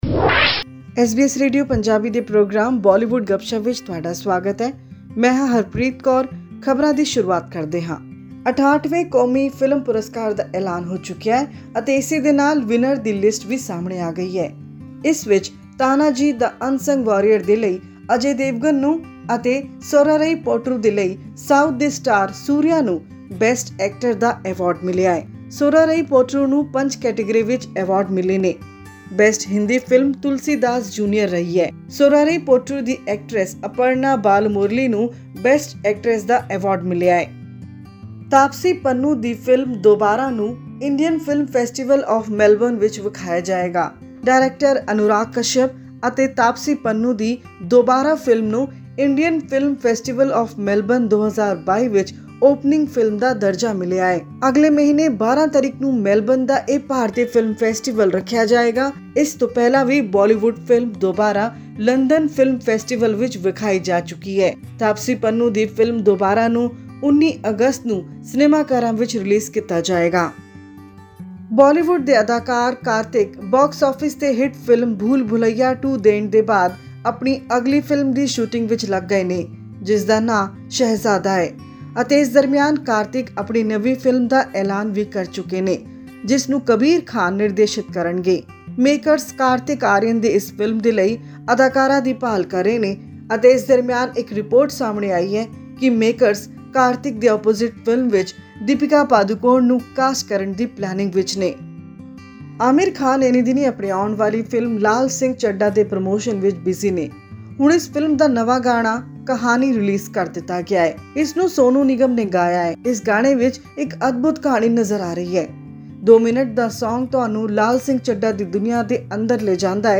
With her debut in Hindi movie 'Kabhi Eid Kabhi Diwali', Shehnaaz Gill has also signed another Bollywood film. This and updates on the Indian Film Festival of Melbourne 2022 in our weekly news bulletin from Bollywood.